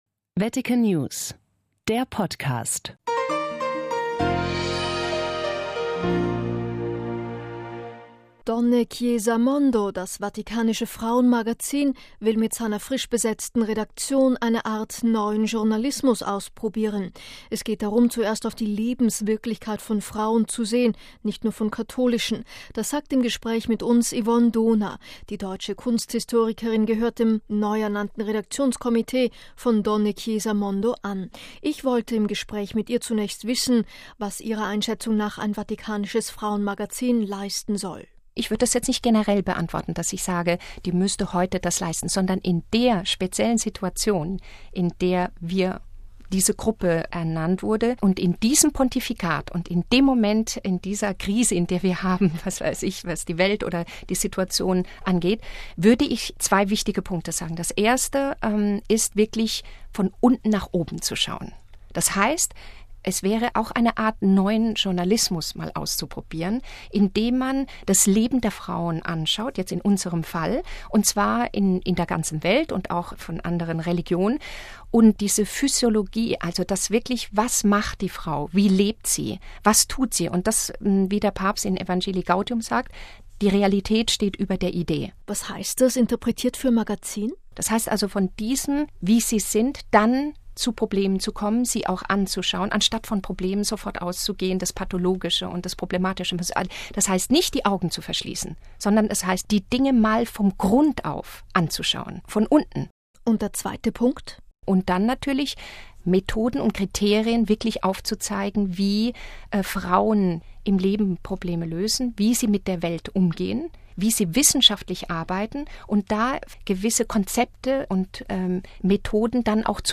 Eine Art neuen Journalismus ausprobieren - Interview